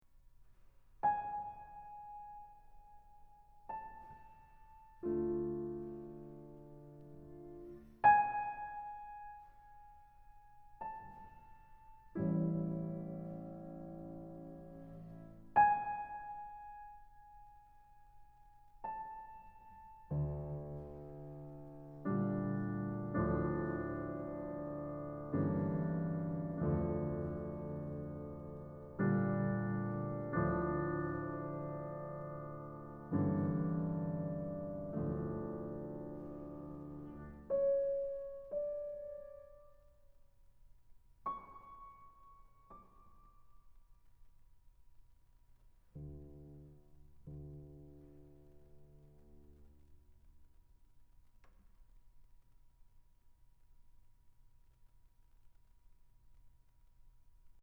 The movement ends with the spirit of hope and light using the little power it has left in a few heartfelt sighs at the top of the piano. But only to be answered by cold darkness which, expectedly, gets the last word.